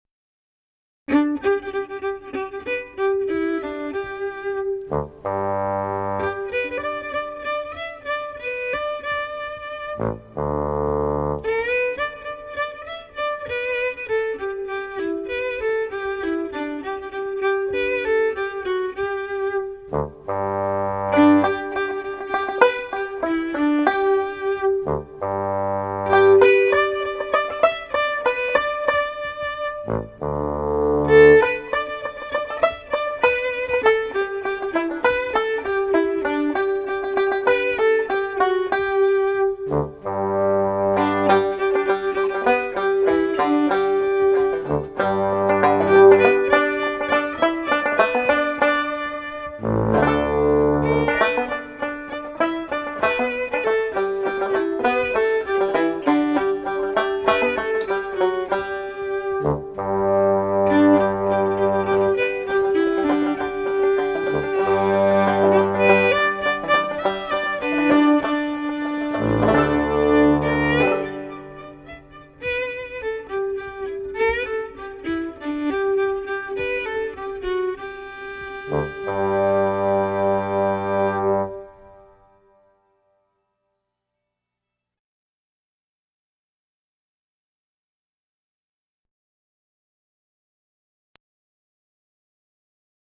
Fiddle Lullabys
Keyboard